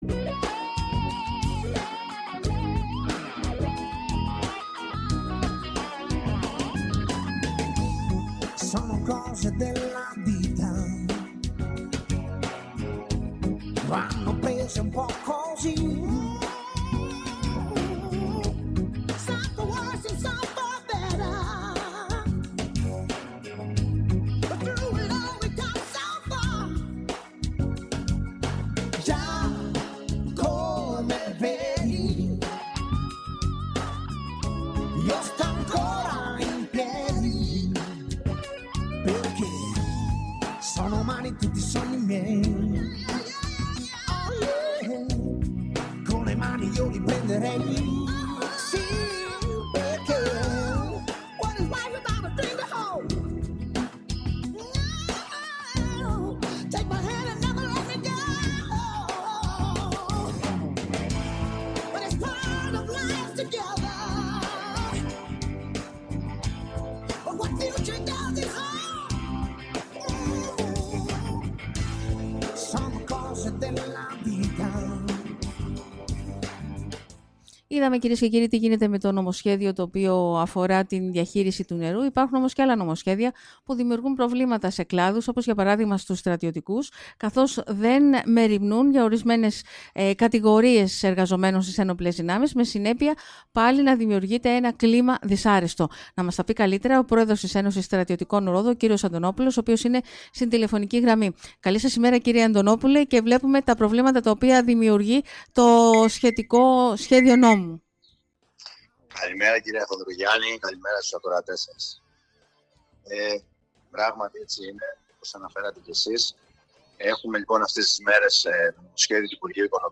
Στα μισθολογικά προβλήματα που αντιμετωπίζουν ορισμένες κατηγορίες εργαζόμενων στις Ένοπλες Δυνάμεις αναφέρθηκε μιλώντας σήμερα στον Sky